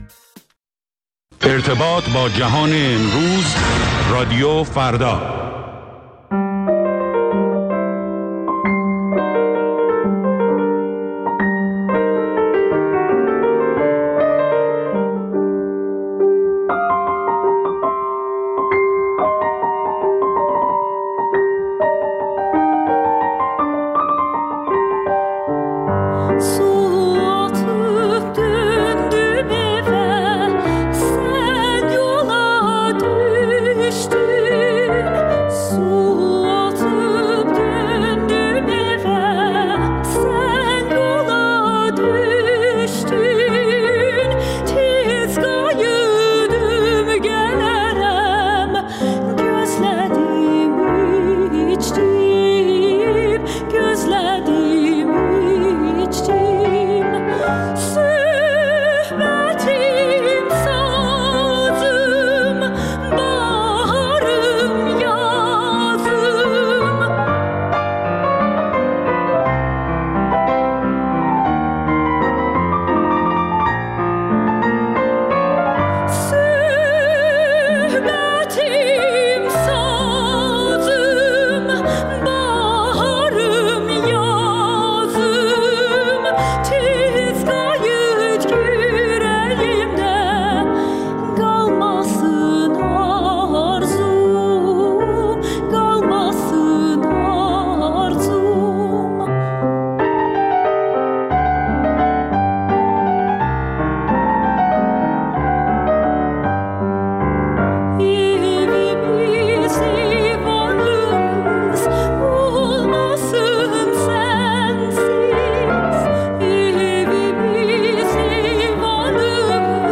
ویژه برنامه موسیقی محلی ایران